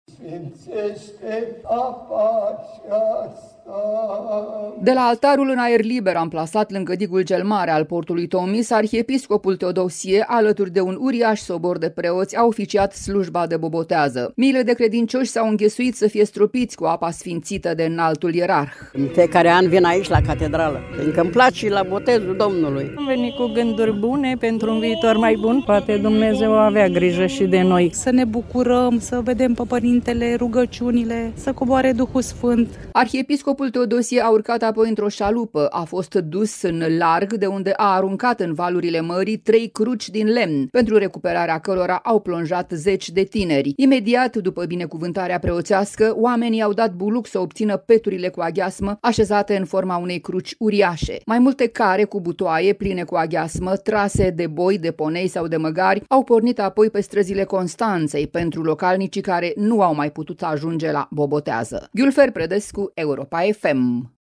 Mii de constănțeni s-au adunat, de Bobotează, în portul Tomis, din Constanța, la Slujba de Sfințire a apelor mării, oficiată de arhiepiscopul Teodosie al Tomisului.
„În fiecare an vin aici, la Catedrală, fiindcă îmi place la botezul Domnului”, a declarat o femeie.